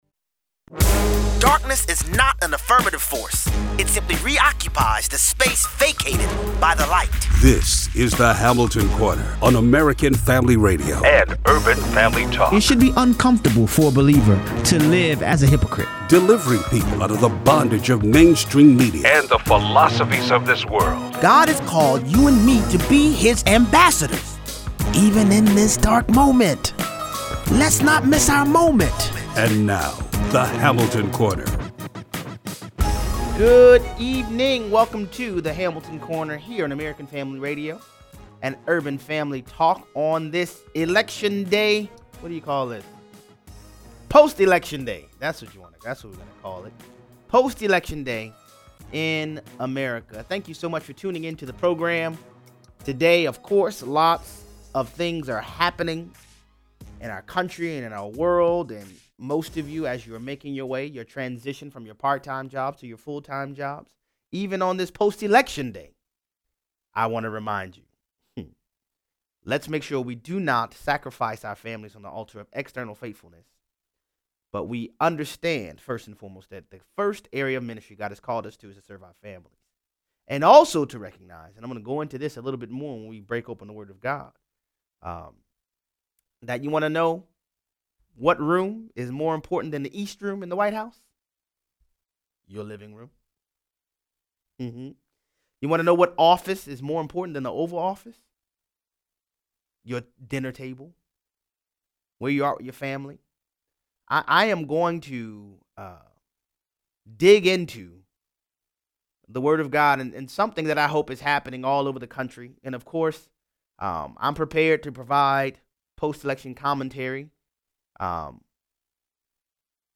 President Trump tangles with “journalists” Jim Acosta and Peter Alexander. 0:43 - 0:60: Jeff Sessions resigns from the Attorney General position. Callers weigh in.